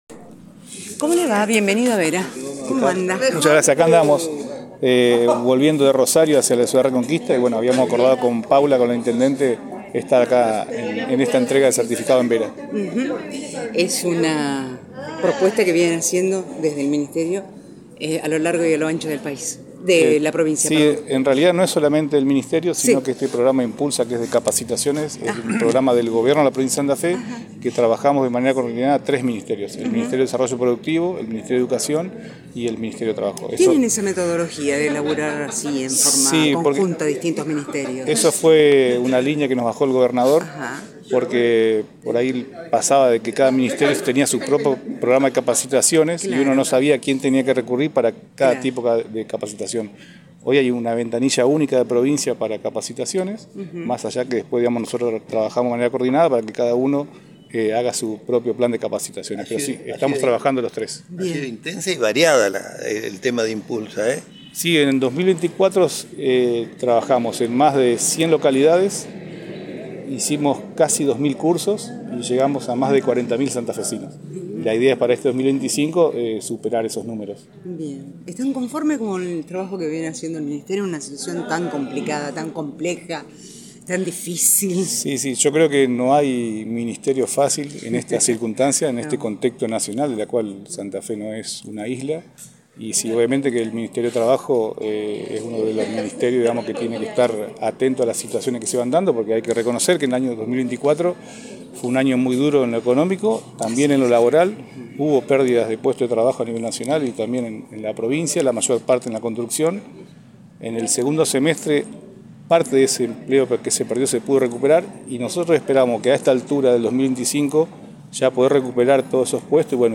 La Intendente Municipal, Paula Mitre, junto al Ministro de Trabajo, Empleo y Seguridad Social, Roal Báscolo, entregaron los certificados de auxiliar electricista, auxiliar soldador, y operador torno-madera. Dialogamos con el Ministro y la Intendente Municipal.